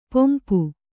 ♪ pompu